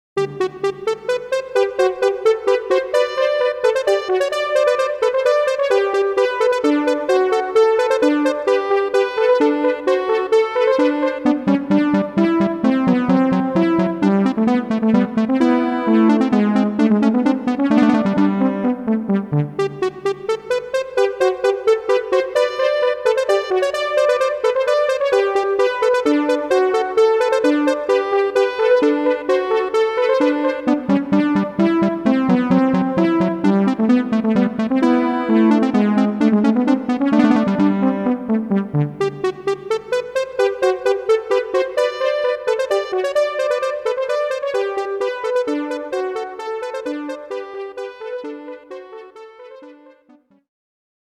as recorded directly from the Roland MT-32 Sound Module